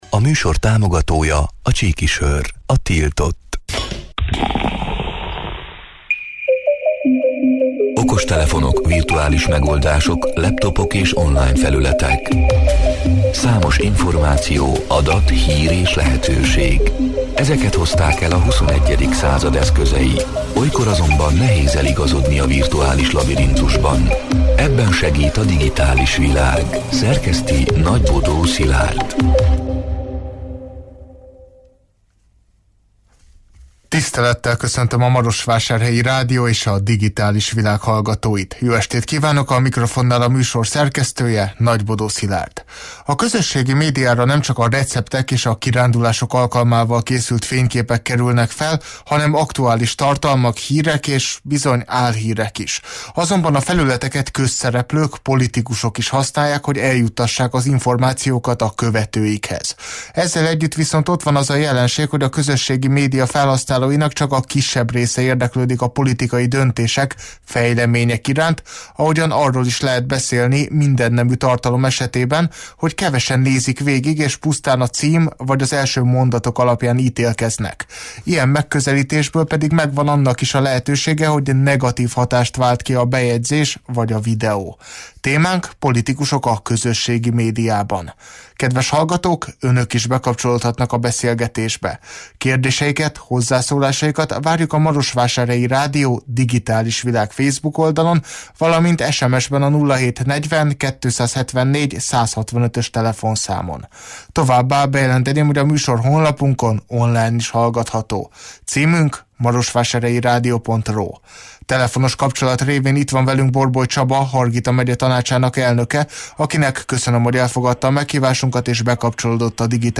A Marosvásárhelyi Rádió Digitális Világ (elhangzott: 2024. augusztus 6-án, kedden este nyolc órától élőben) c. műsorának hanganyaga: A közösségi médiára nemcsak a receptek és a kirándulások alkalmával készült fényképek kerülnek fel, hanem aktuális tartalmak, hírek és bizony álhírek is.